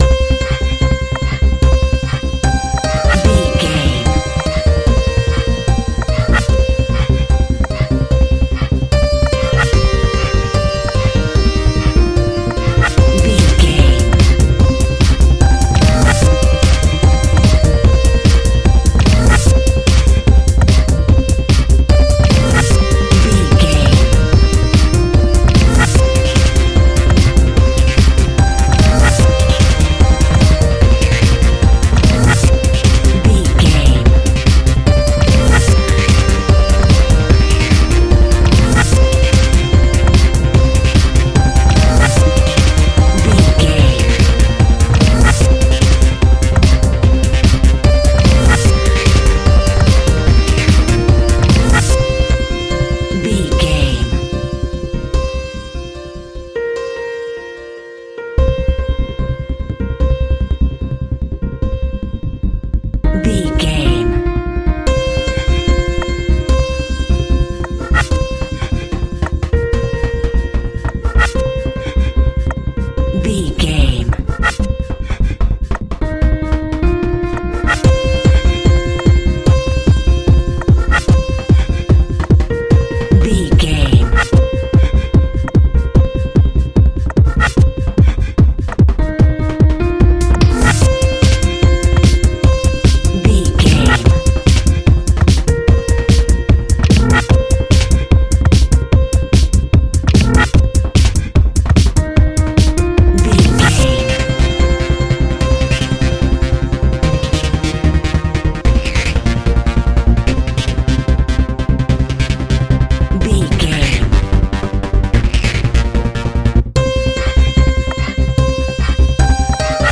Epic / Action
Fast paced
Aeolian/Minor
groovy
uplifting
futuristic
driving
energetic
repetitive
piano
drum machine
synthesiser
acid trance
uptempo
synth leads
synth bass